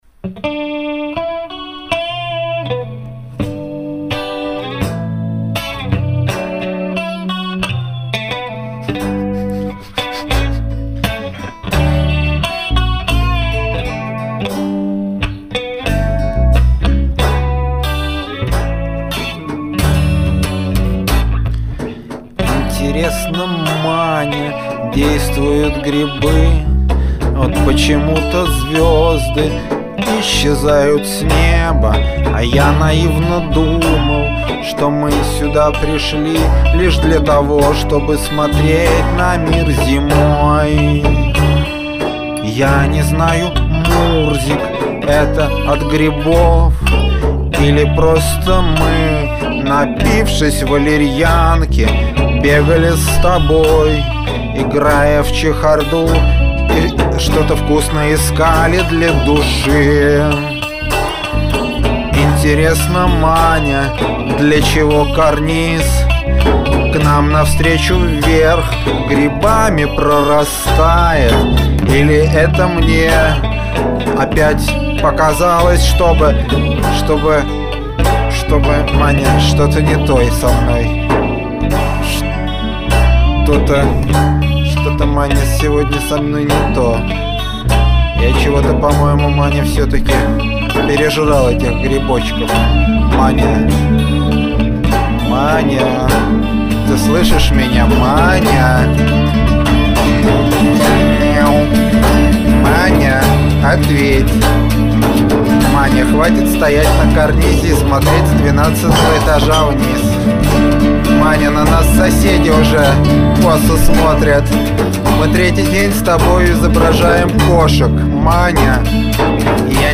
голос
бас-гитара
ударные, клавиши    Обложка